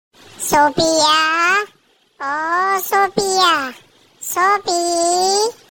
goat calling Sophia name sound effects free download